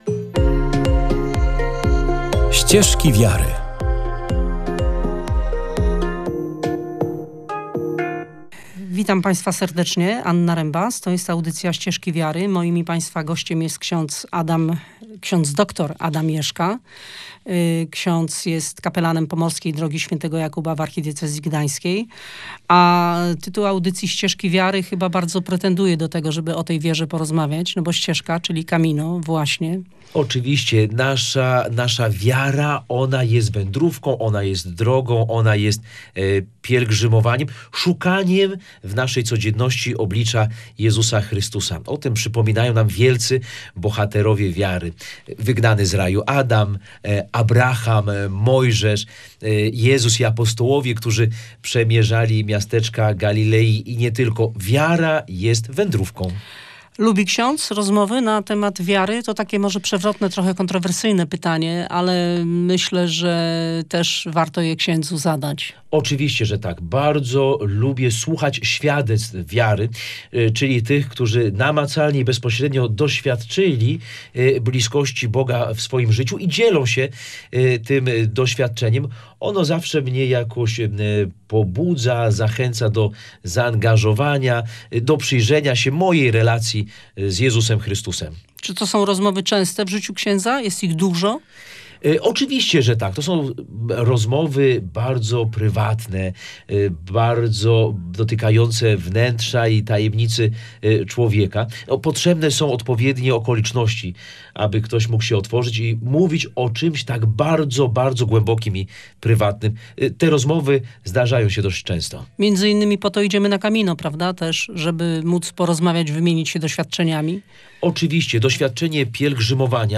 Od Nawarry po Straszyn – rozmowa